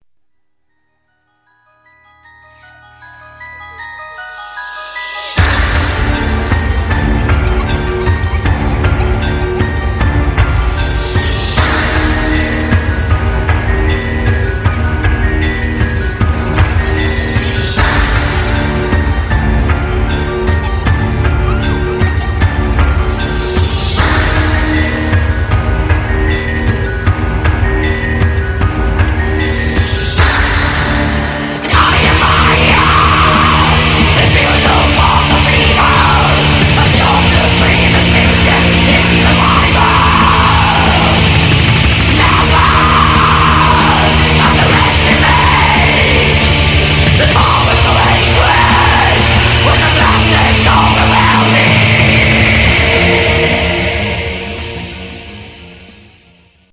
Black Metal Sound Files